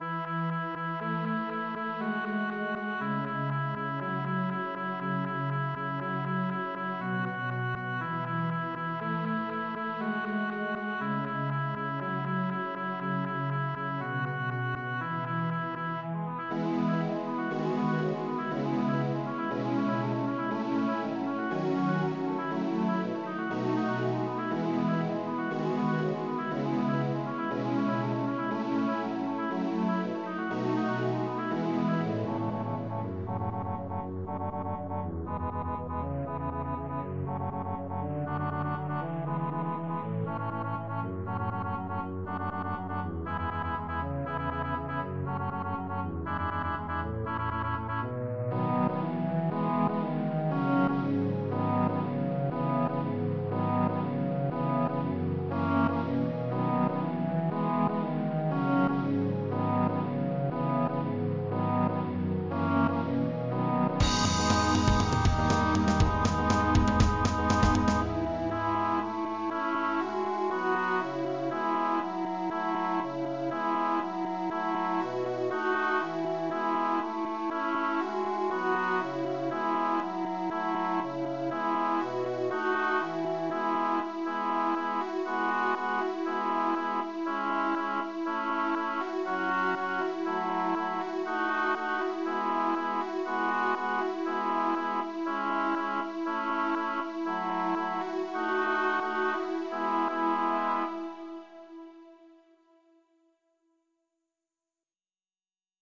「BGM」